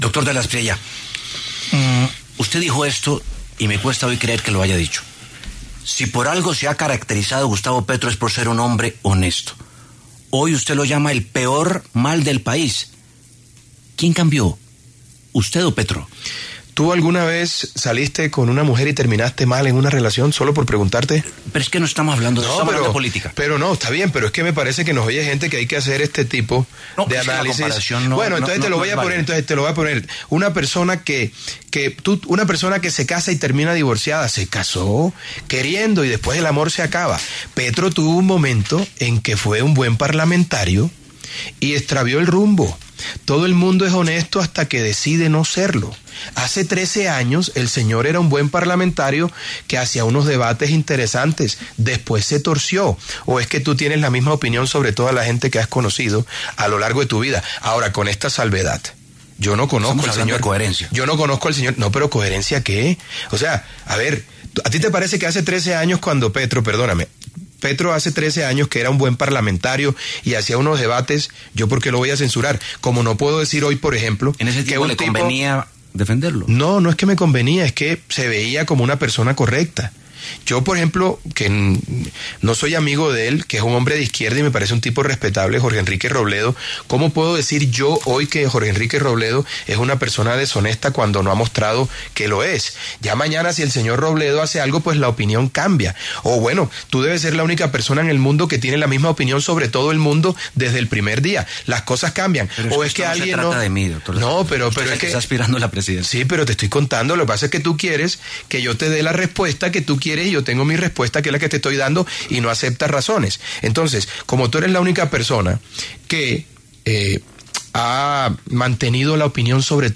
Abelardo de la Espriella, precandidato presidencial, pasó por los micrófonos de La W, con Julio Sánchez Cristo, para hablar de su campaña electoral en la que no le cerró la puerta a una posible unión con el Centro Democrático y la derecha colombiana para “derrotar al petrismo”.